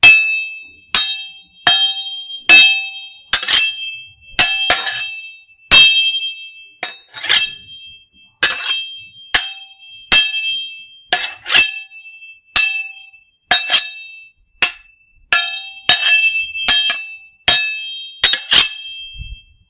swordfight-3.ogg